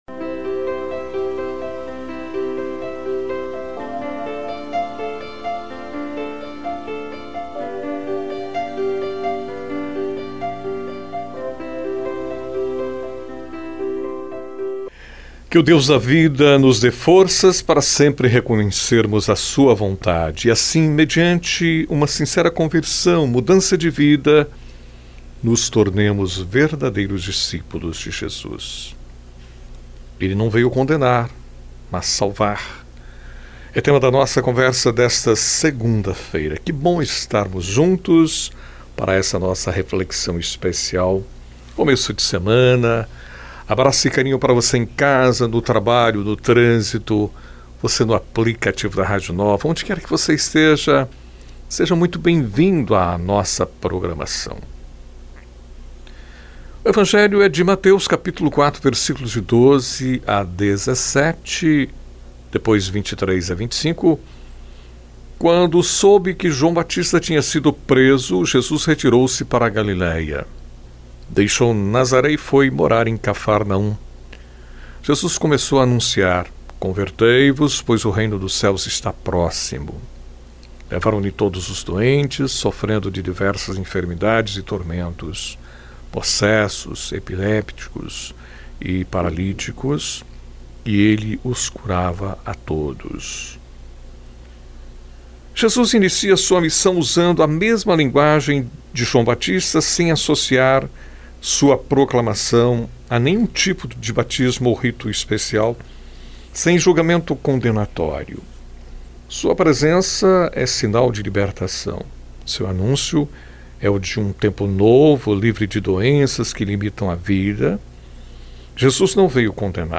na voz do locutor